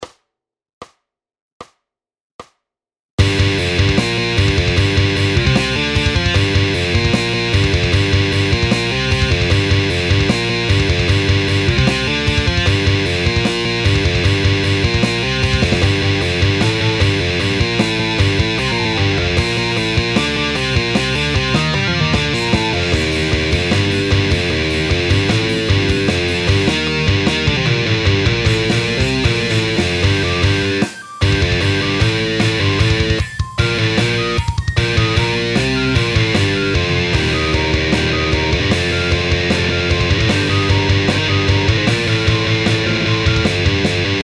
Progressive Riff Lesson Exercise - 1 || Metal Riff Guitar Exercise
Very Technical Intermediate Progressive Riff. Started in 4/4 but later multiple signature changes for example 9/8, 3/8, 2/4
Do the riff with Alternate Picking.
Progressive-Guitar-Riff-Lesson-1.mp3